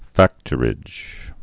(făktər-ĭj)